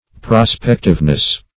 Prospectiveness \Pro*spec"tive*ness\, n.
prospectiveness.mp3